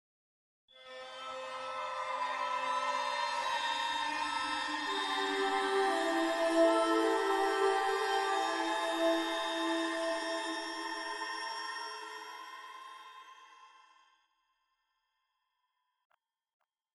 Nenie, filastrocche e cantilene inquietanti
In questo esempio ho usato un effetto sonoro come sfondo e una voce di bambino.
Per aumentare il senso spettrale ho usato il riverbero di una sala molto grande, e poi ho modificato il pan in modo che la voce sembri provenire non dal centro ma lateralmente.
horror-infanzia.mp3